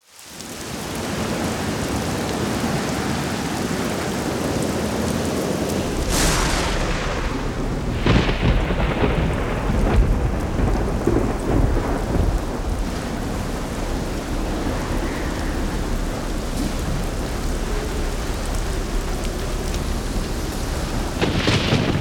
Звуки шторма
Шум дождя, порывы ветра, раскаты грома и вспышки молнии